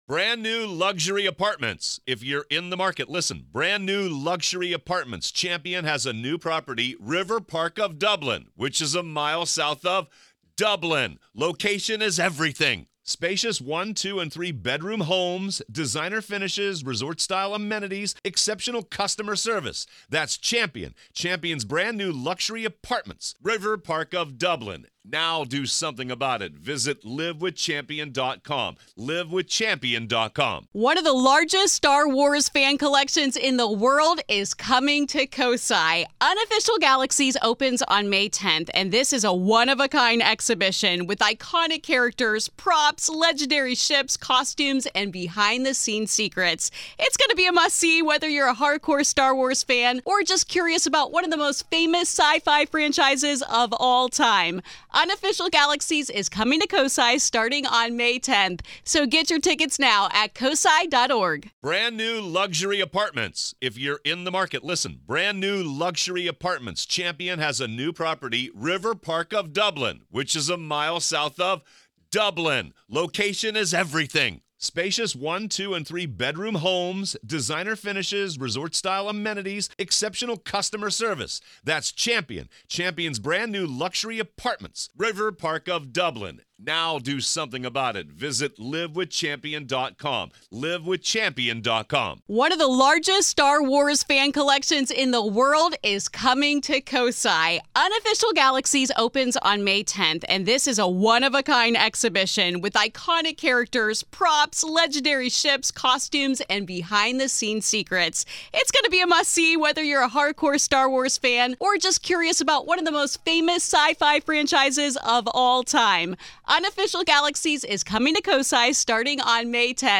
From the best supernatural & paranormal podcast, Real Ghost Stories Online! Haunting real ghost stories told by the very people who experienced these very real ghost stories.